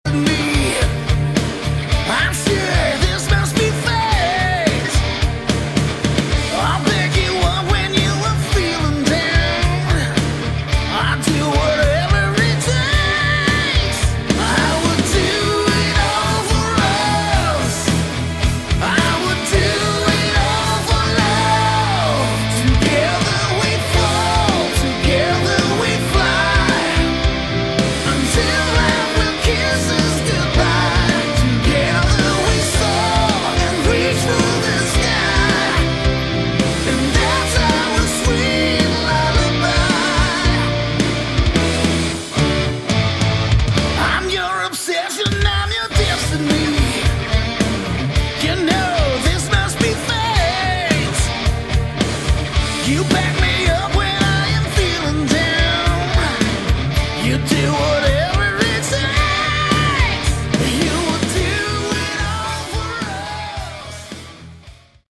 Category: Hard Rock
Vocals
Keyboards
Bass
Drums
Guitar